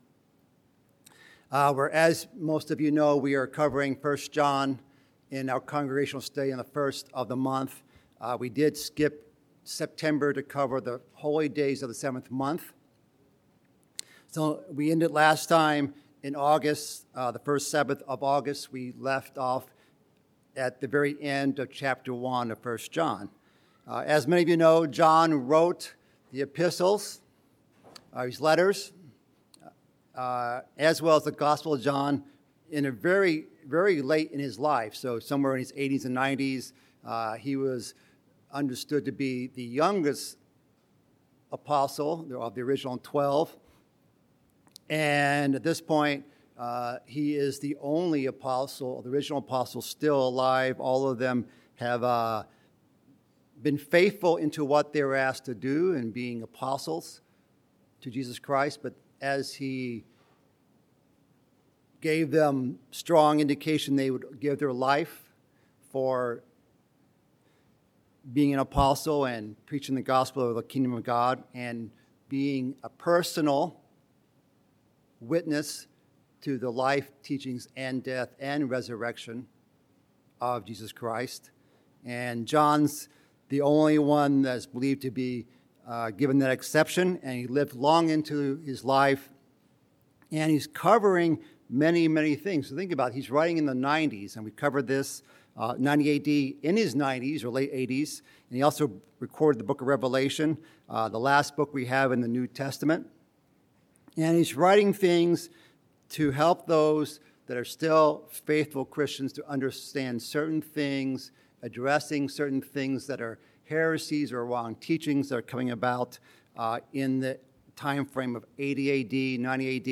Bible Study: 1 John
Given in Chicago, IL